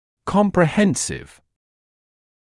[ˌkɔmprɪ’hensɪv][ˌкомпри’хэнсив]комплексный, всеобъемлющий, полный